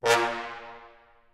Fat_Horn_3.wav